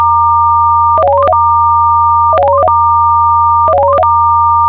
rtms_paging.wav